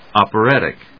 音節op・er・at・ic 発音記号・読み方
/ὰpərˈæṭɪk(米国英語), ˌɑ:pɜ:ˈætɪk(英国英語)/